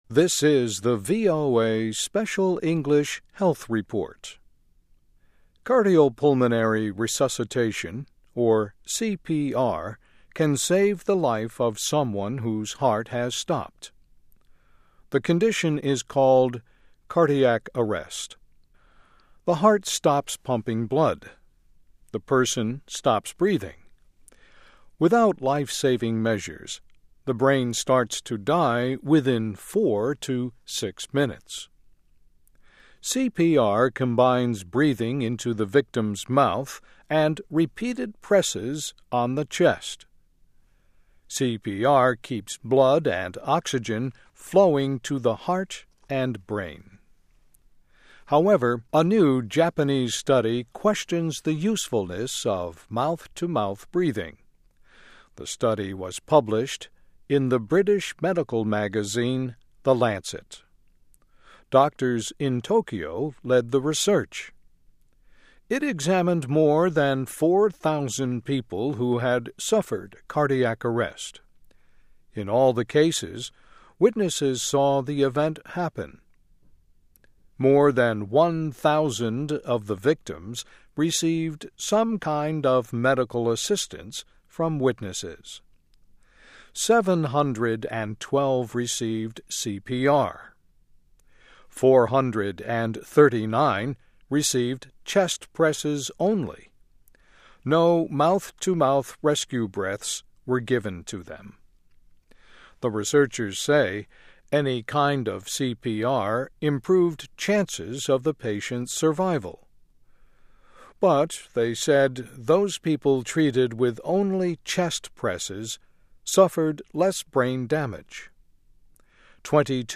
Medical: Chest Compressions May Be Most Important Part of CPR (VOA Special English 2007-03-27)